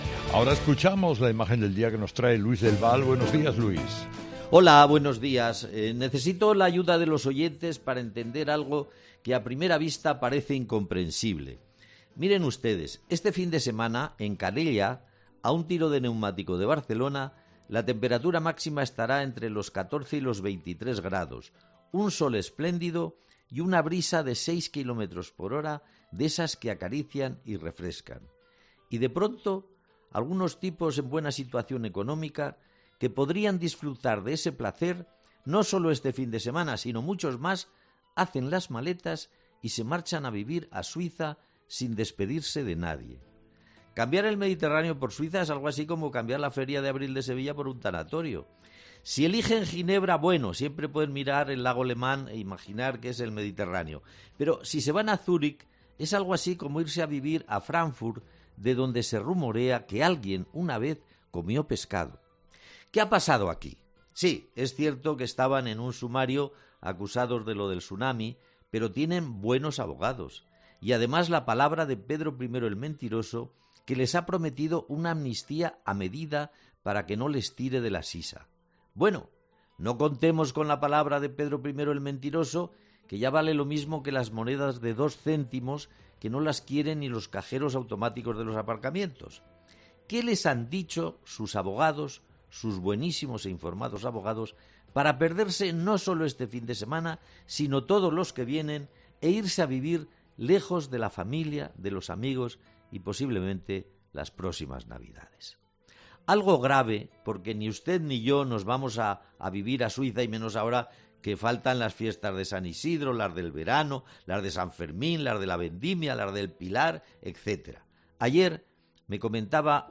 Luis del Val habla en su 'Imagen del día' en 'Herrera en COPE' sobre la fuga de los acusados por Tsuami a Suiza